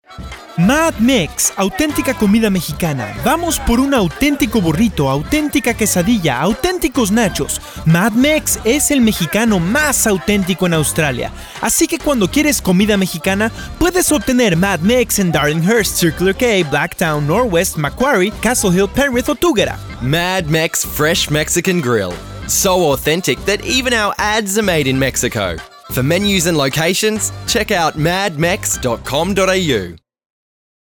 To enforce this idea, the campaign – titled Autentica Mexicana – has been led by a radio ad that was produced in Mexico.